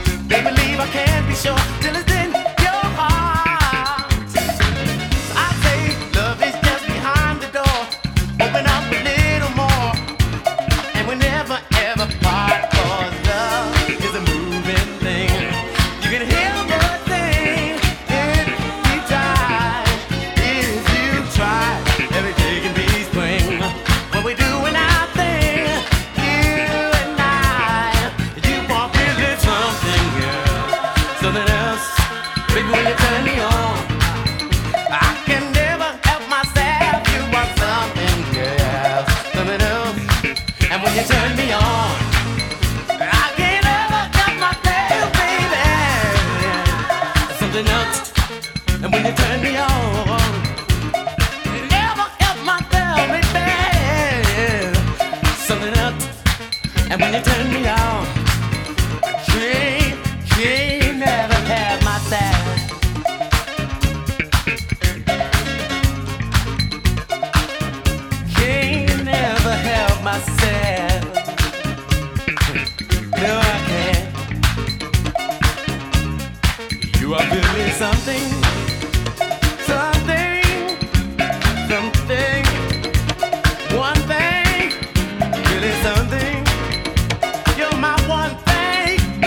Disco House Funk